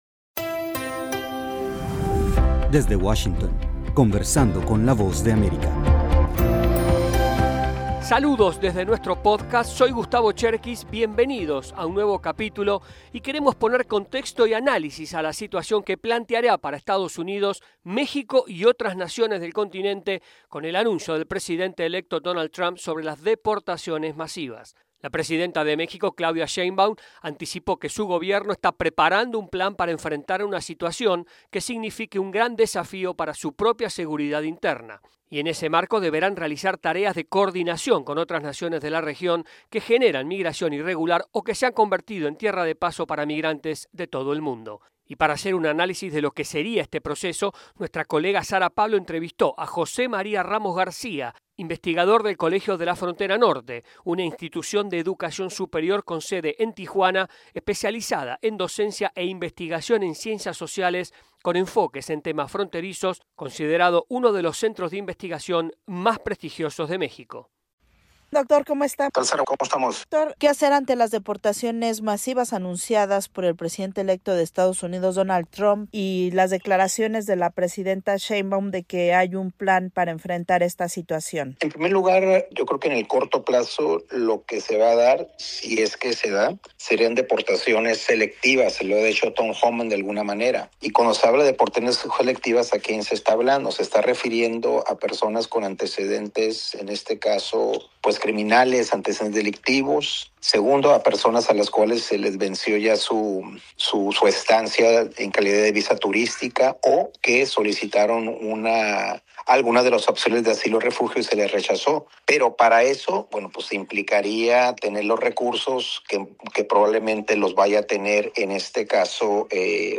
Para analizar las perspectivas conversamos con el especialista